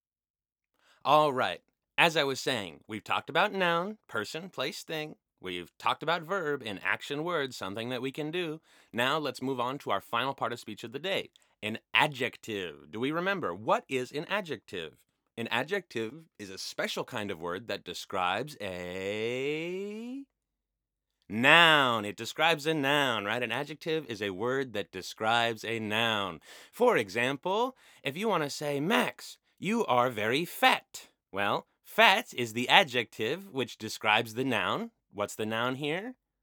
以下是三种场景下真人音色与AIGC音色的对比：
英文教学_真人录音
英文教学_真人录音_demo.wav